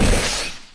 loader_launch.ogg